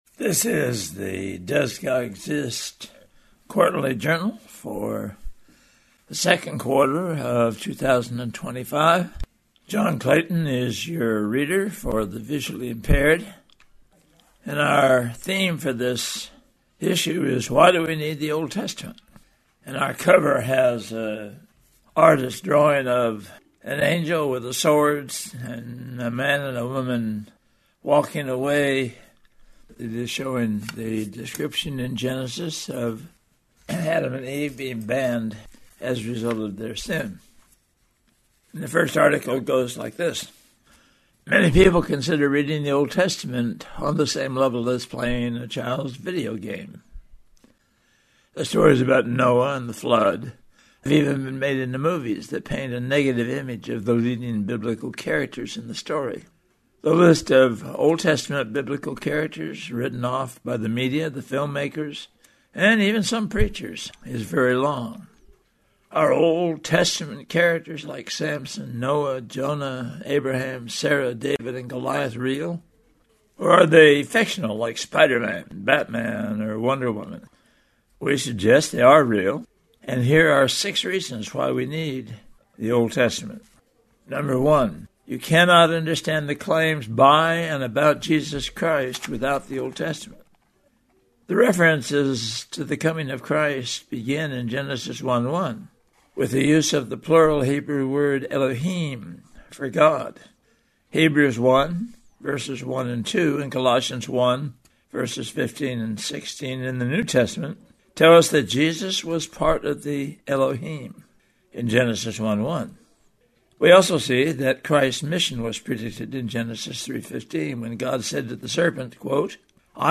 For the visually impaired we are providing an audio version of our journal online.